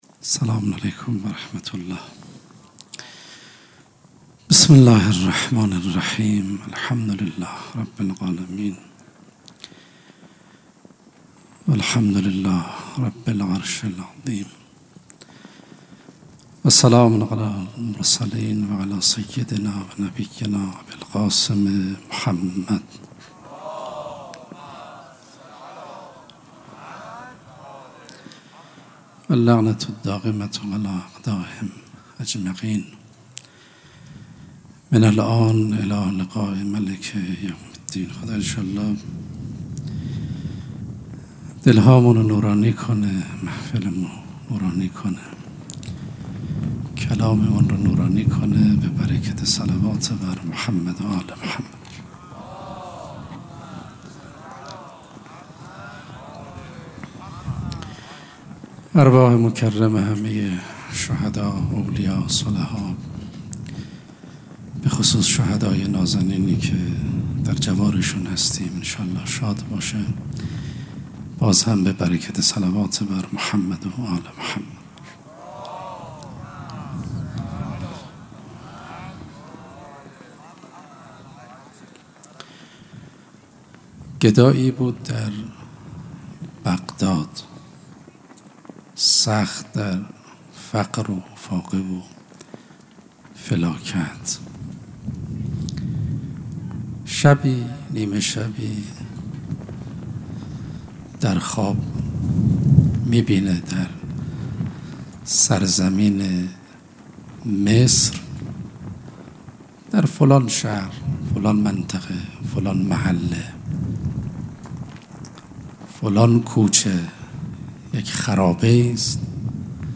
سخنرانی
شب نهم محرم99